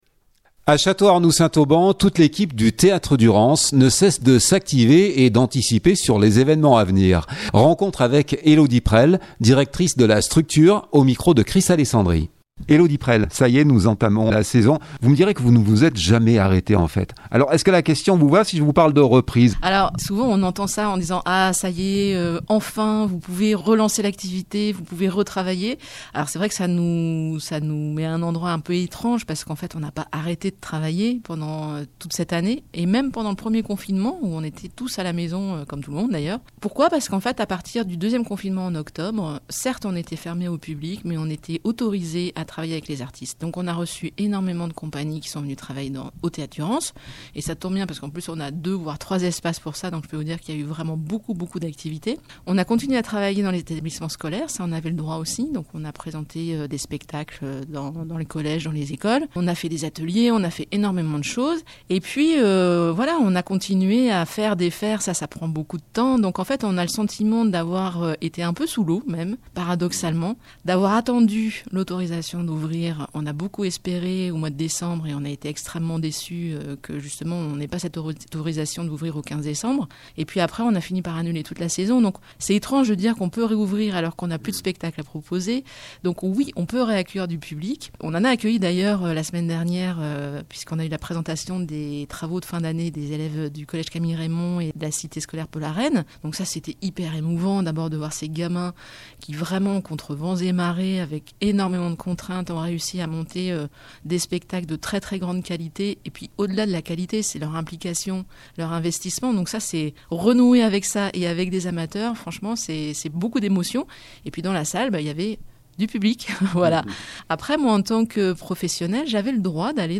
A Château-Arnoux Saint-Auban, toute l’équipe du Théâtre Durance ne cesse de s’activer et d’anticiper sur les évènements à venir, rencontre avec